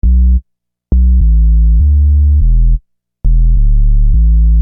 Bass 25.wav